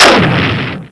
Explosion3.wav